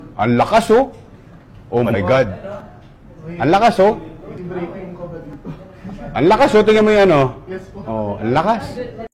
ang-lakas-oh-fyp-valorantph-valorant-funny-gaming-valorantgaming.mp3